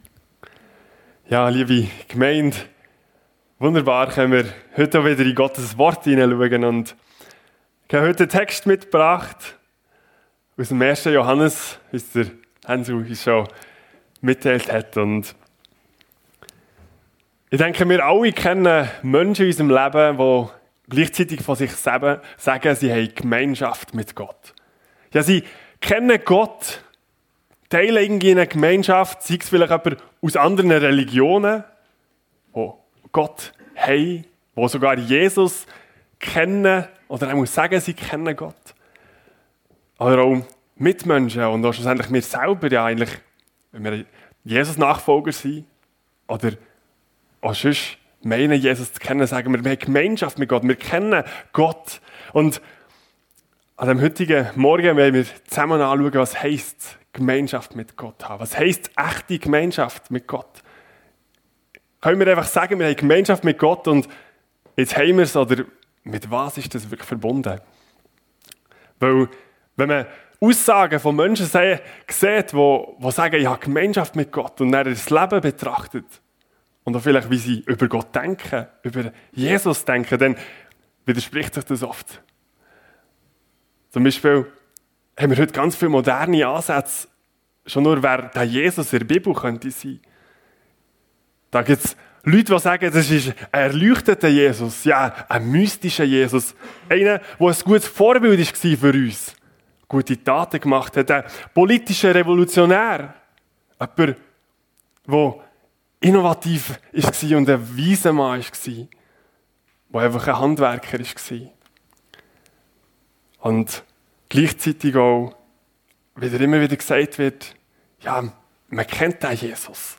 1. Johannes 1, 1-10 ~ FEG Sumiswald - Predigten Podcast